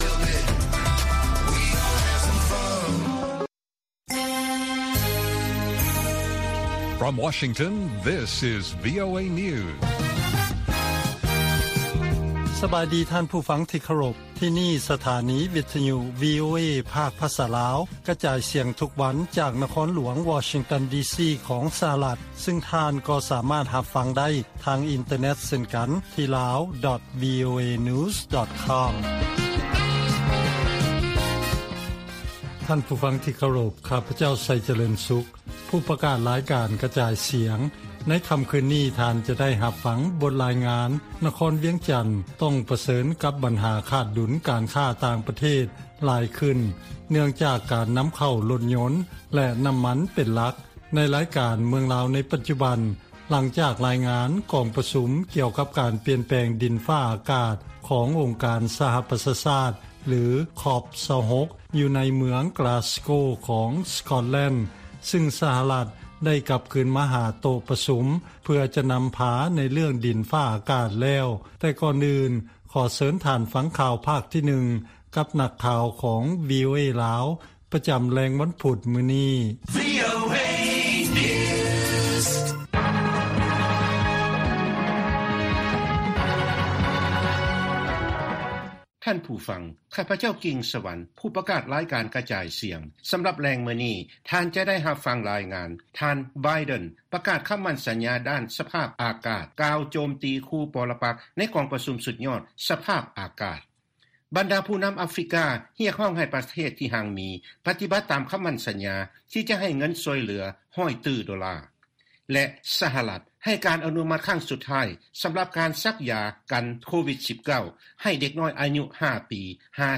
ວີໂອເອພາກພາສາລາວ ກະຈາຍສຽງທຸກໆວັນ. ຫົວຂໍ້ຂ່າວສໍາຄັນໃນມື້ນີ້ມີ: 1) ນະຄອນຫລວງວຽງຈັນ ປະເຊີນກັບບັນຫາຂາດດຸນການຄ້າຫລາຍຂຶ້ນ ຍ້ອນການນໍາເຂົ້າລົດຍົນ ແລະ ນໍ້າມັນເປັນຫລັກ.